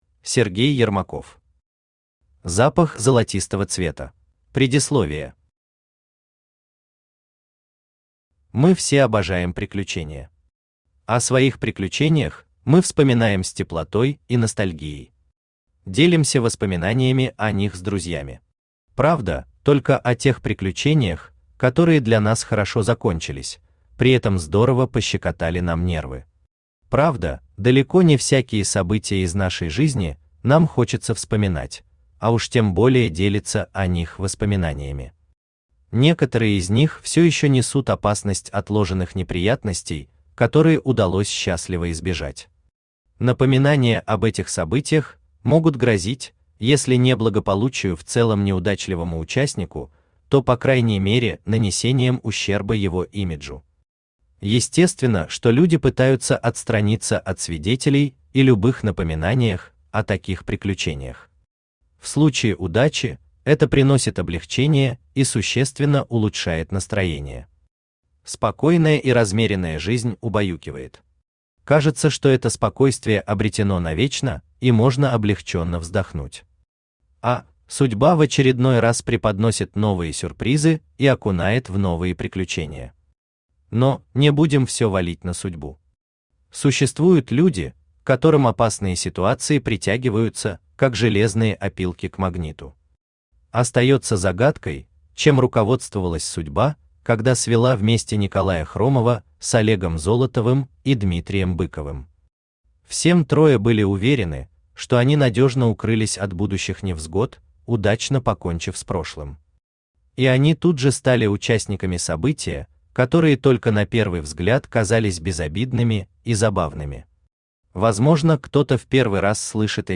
Aудиокнига Запах золотистого цвета Автор Сергей Максимович Ермаков Читает аудиокнигу Авточтец ЛитРес.